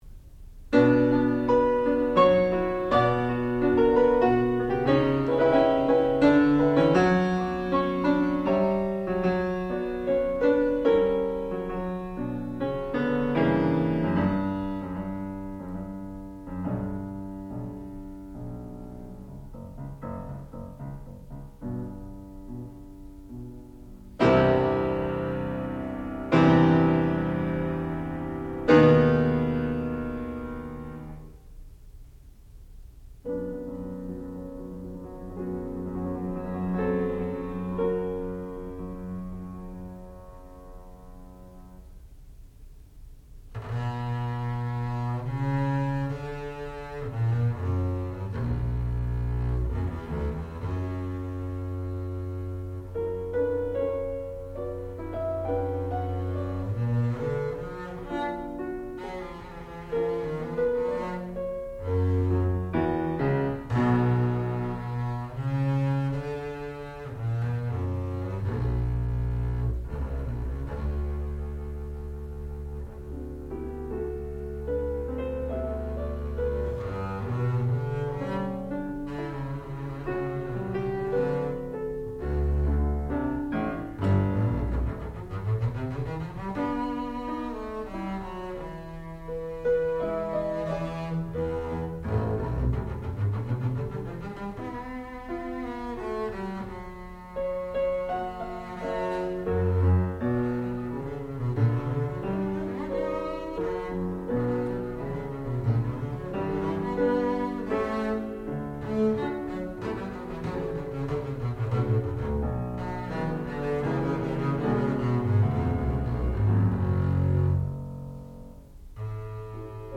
sound recording-musical
classical music
double bass
piano
Qualifying Recital